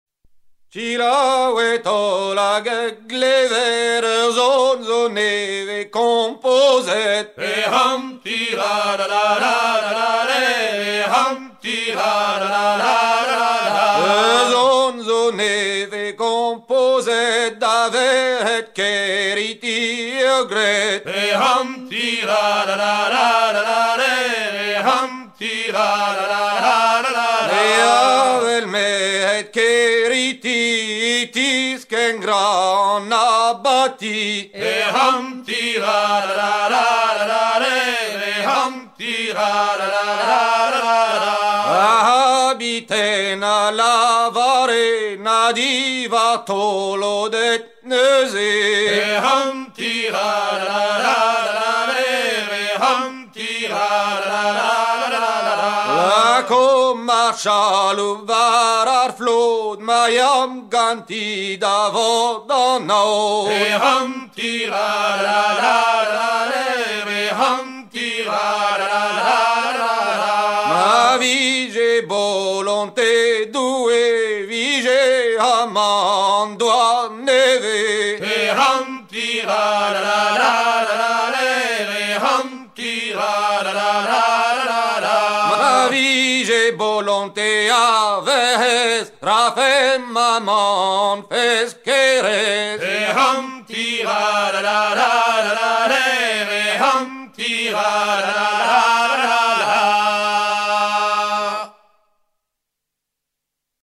gestuel : à hisser main sur main
circonstance : maritimes